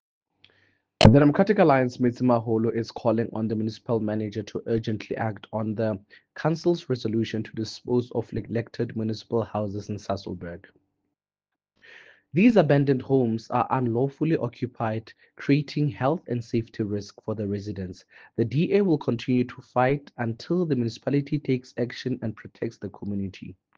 English soundbite by Cllr Teboho Thulo and